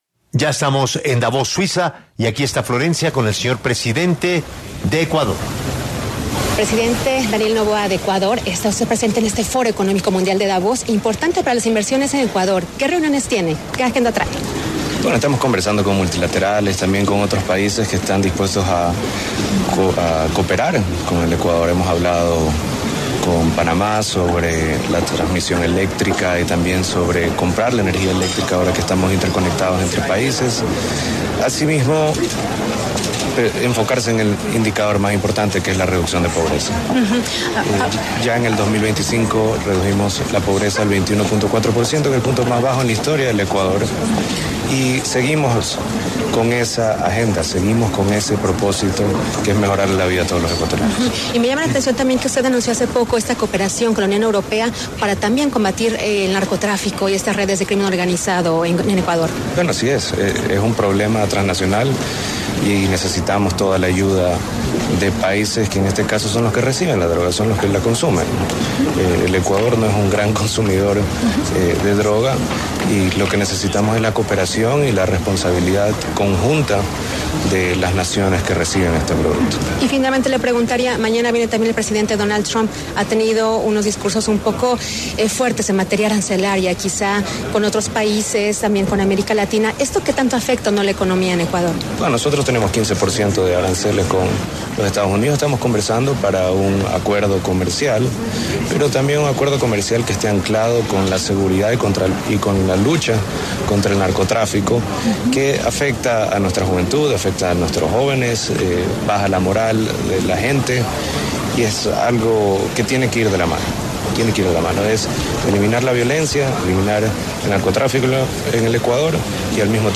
Daniel Noboa, presidente de Ecuador, habla desde el Foro Económico Mundial en Davos